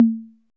Index of /90_sSampleCDs/Roland - Rhythm Section/DRM_Drum Machine/KIT_TR-606 Kit
PRC 808 CO0D.wav